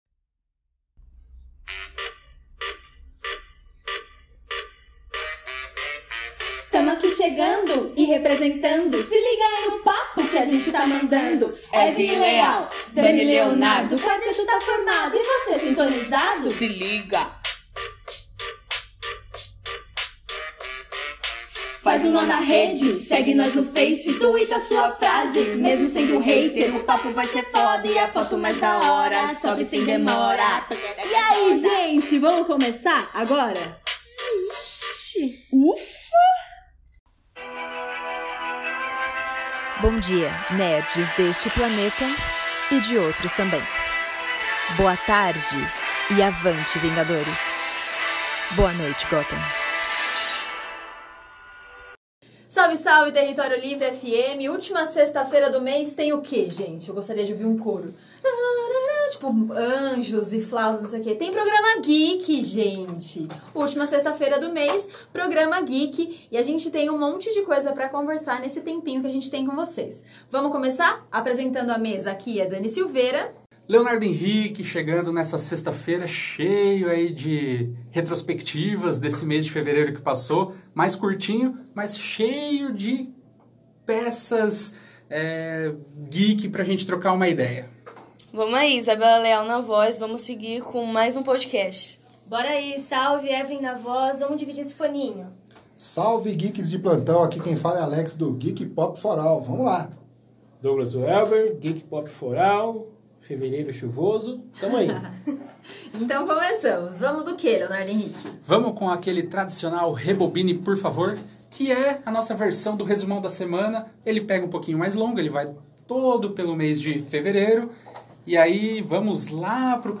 Uma breve retrospectiva e bate-papo sobre o que houve de mais nerd no mês de fevereiro: o teaser das séries do MCU, The Batman; e, ainda, as expectativas para o evento Fuzuê Nerd, que acontece em SP nos dias 7 e 8 de março.